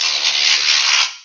elec_shock.wav